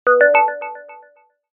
Minimal UI Notification Melody
A short, high-pitched electronic melody designed for digital alerts and user interface feedback. Ideal for app notifications, UI interactions, system messages, and digital bell cues. Clean, minimalist electronic ping that fits modern apps, games, and software interfaces.
Genres: Sound Effects
Minimal-ui-notification-melody.mp3